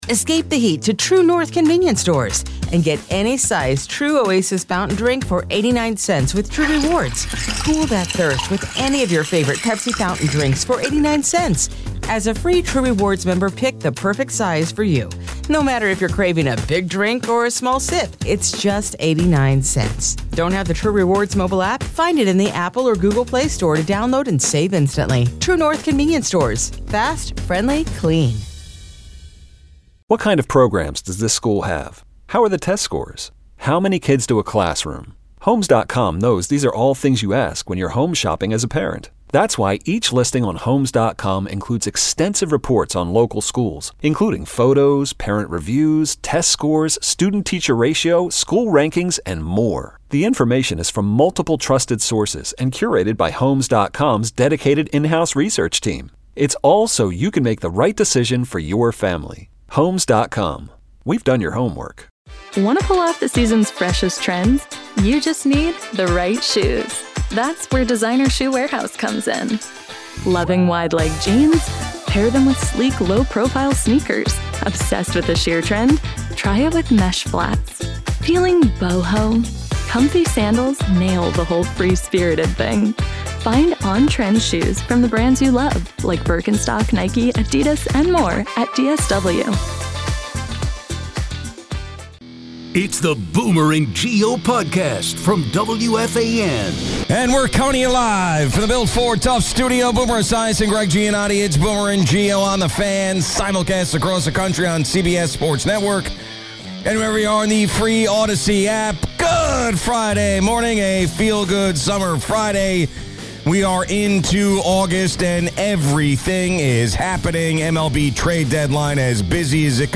On a post-trade deadline Friday, Boomer and Gio praise the Yankees and Mets for their aggressive but smart trade moves. The Yankees acquired three back-end relievers, while both teams avoided sacrificing top prospects.